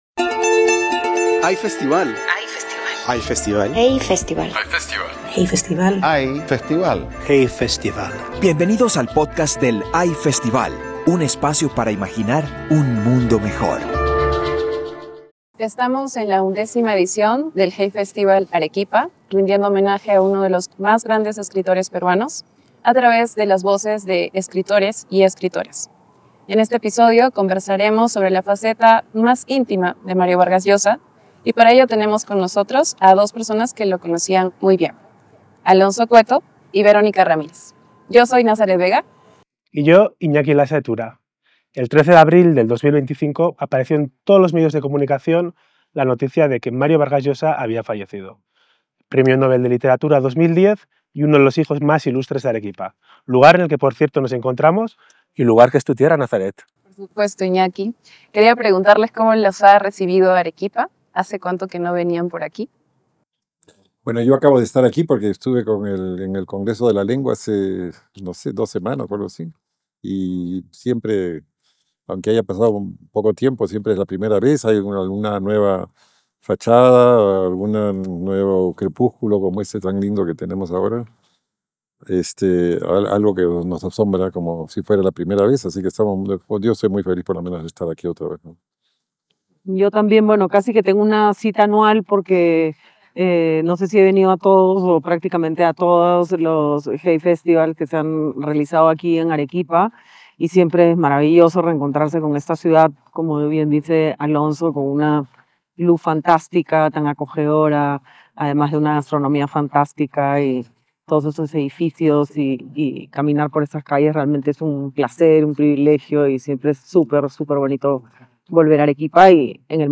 El 13 de abril de 2025 apareció en todos los medios de comunicación la noticia del fallecimiento de Mario Vargas Llosa, premio nobel de literatura 2010 y uno de los hijos más ilustres de Arequipa, ciudad desde la que grabamos este pódcast en homenaje a su figura durante el Hay Festival Arequipa 2025.